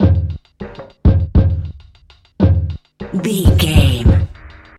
Electronic loops, drums loops, synth loops.,
Epic / Action
Fast paced
In-crescendo
Ionian/Major
Fast
aggressive
industrial
groovy
heavy
hypnotic